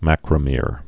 (măkrə-mîr)